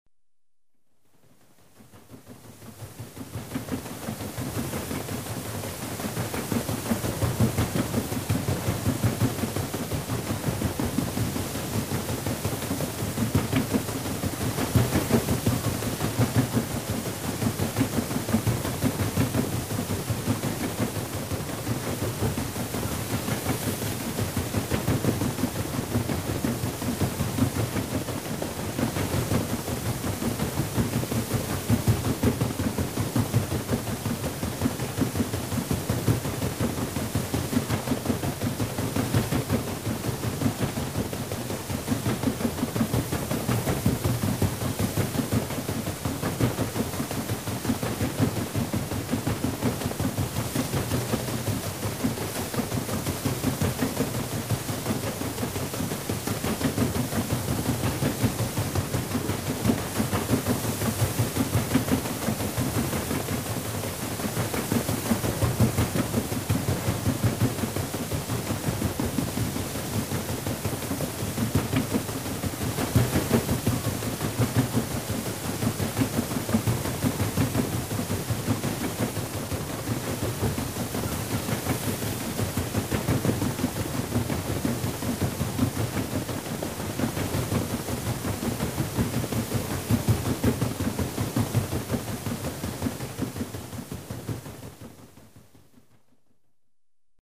Звуки кораблей
Здесь вы найдете шум волн, скрип канатов, гудки пароходов и другие аутентичные аудио.